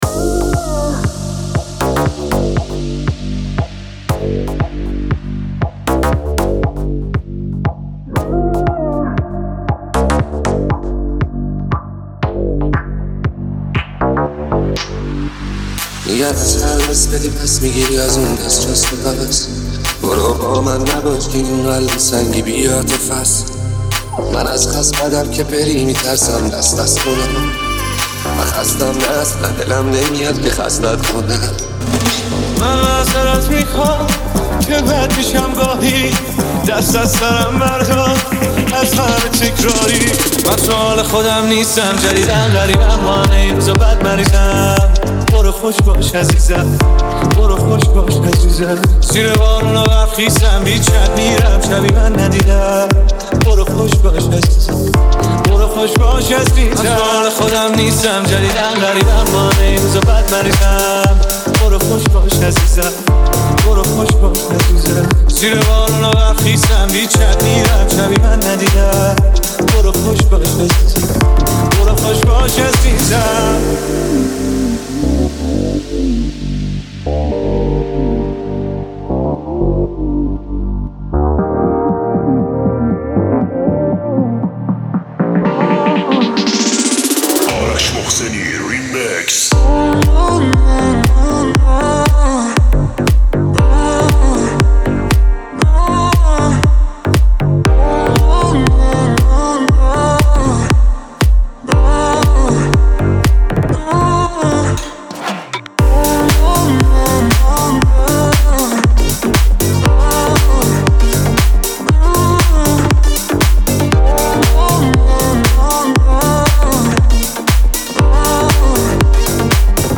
آهنگ ریمیکس شاد